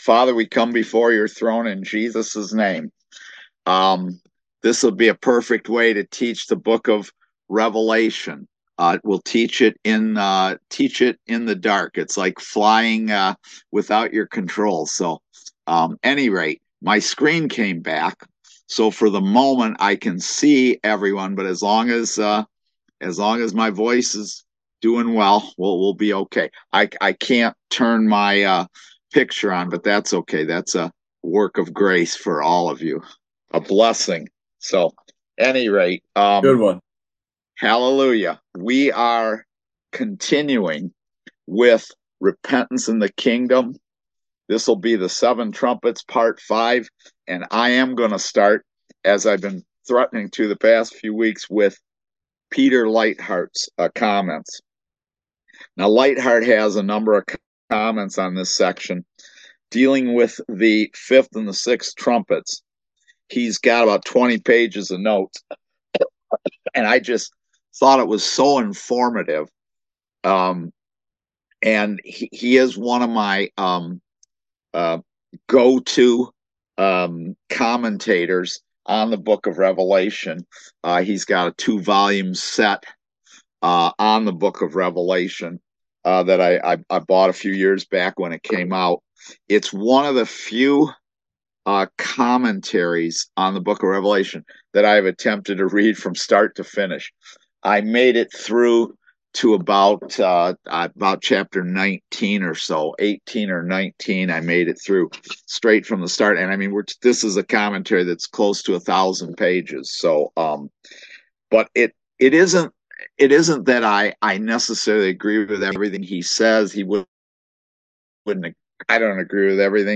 Series: Eschatology in Daniel and Revelation Service Type: Kingdom Education Class